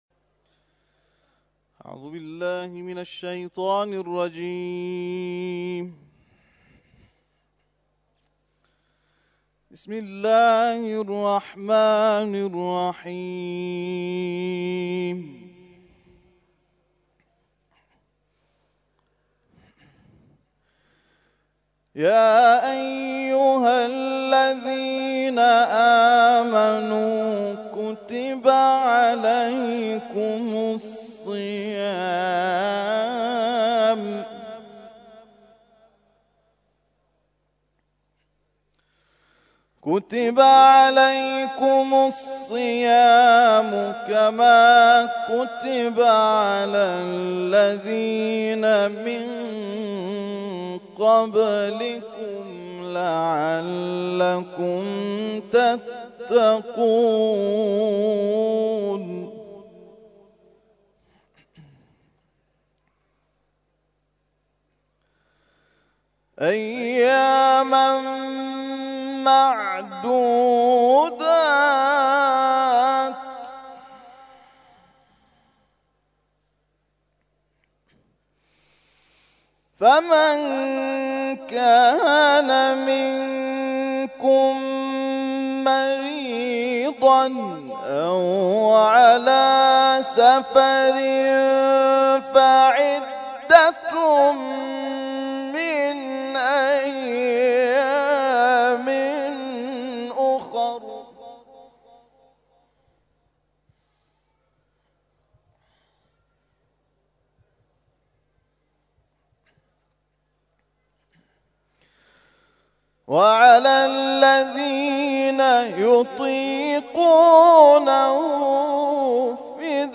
تلاوت مغرب - سوره بقره آیات ( ۱۸۳ الی ۱۸۶) Download